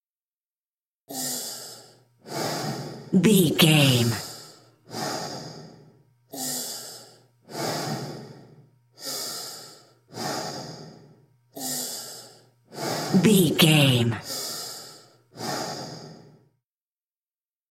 Hospital respiratory
Sound Effects
Atonal
chaotic
anxious